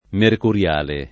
vai all'elenco alfabetico delle voci ingrandisci il carattere 100% rimpicciolisci il carattere stampa invia tramite posta elettronica codividi su Facebook mercuriale [ merkur L# le ] agg. e s. f. — sim. il pers. m. stor.